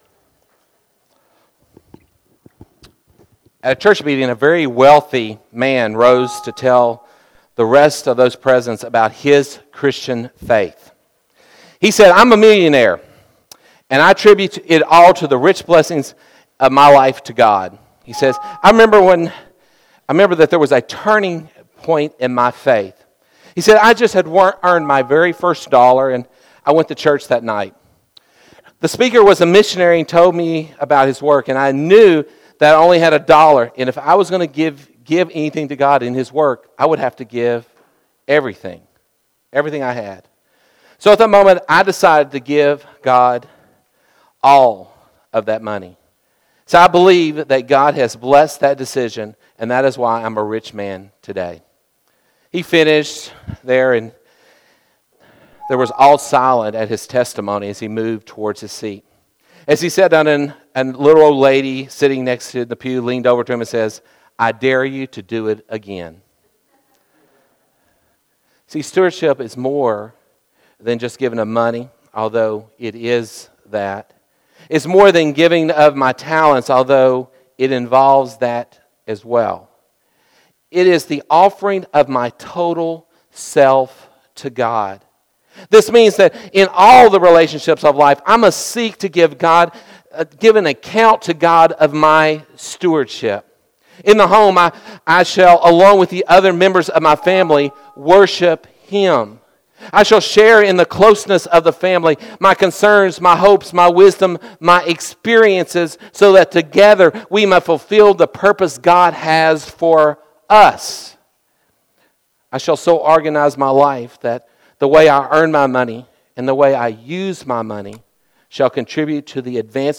Audio Sermons - Babcock Road Christian Church